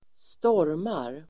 Uttal: [²st'år:mar]
stormar.mp3